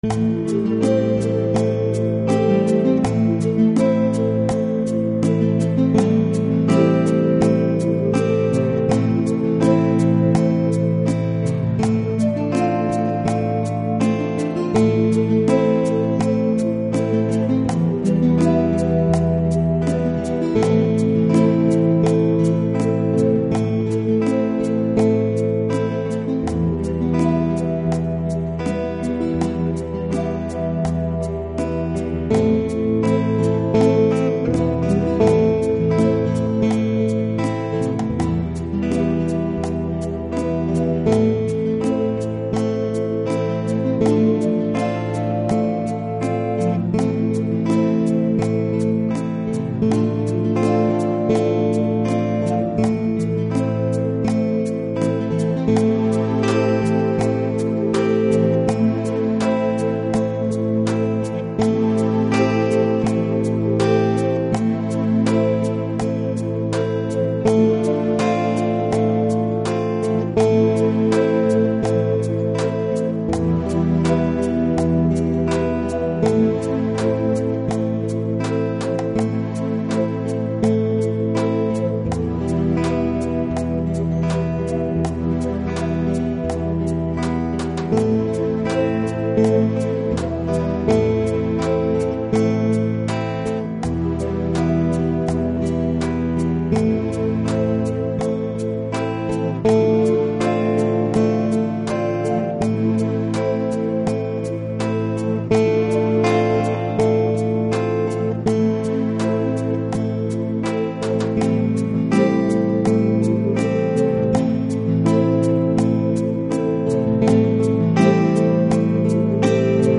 A quiet song written expecially for Christmas Eve.